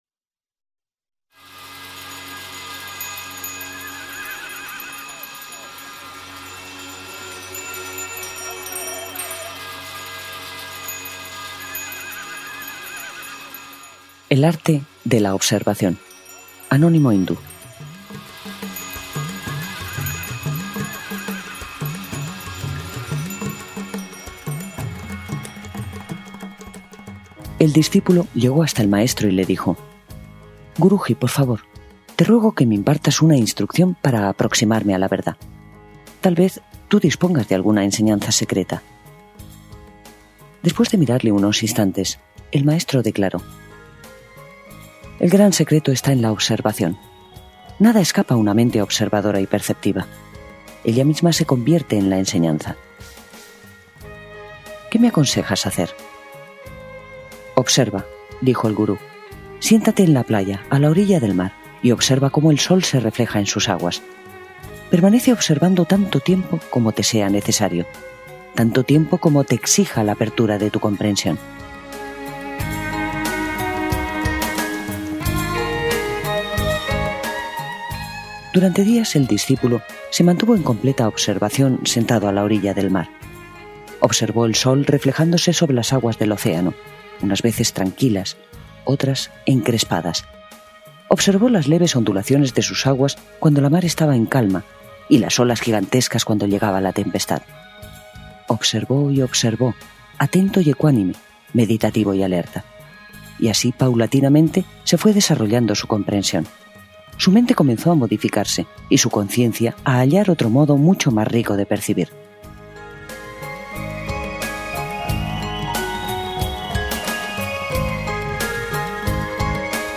Audiolibro: El arte de la observación
Música: Caerou (cc:by-sa)